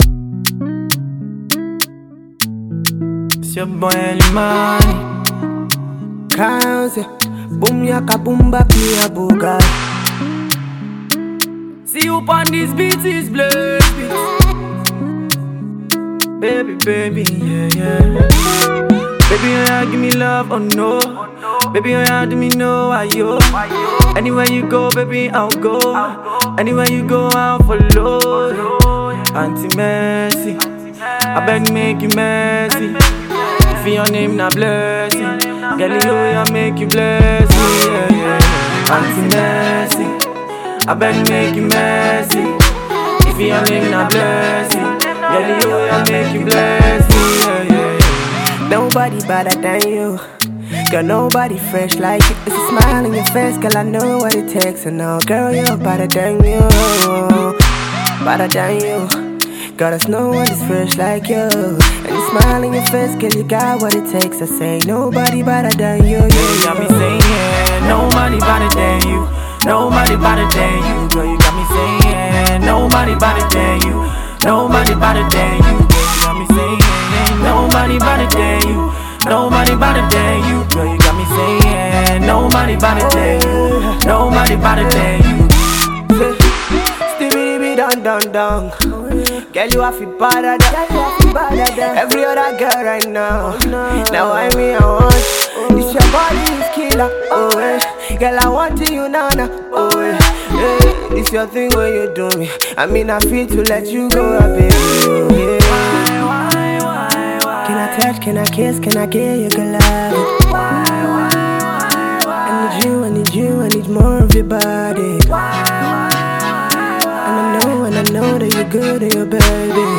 an upbeat mild tempo banger for the clubs